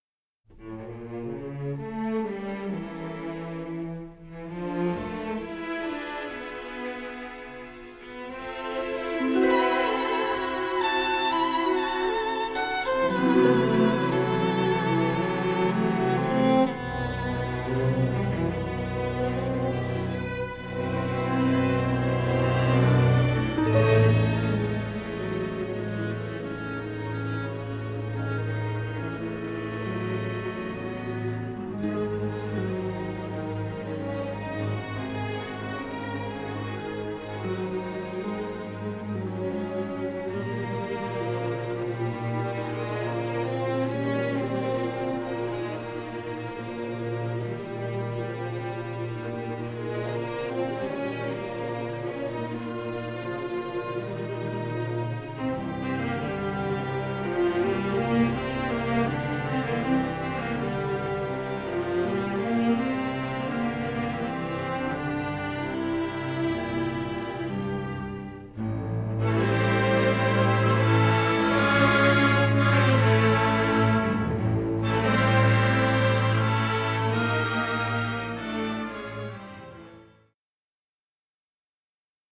rich symphonic score
three-channel stereo scoring session masters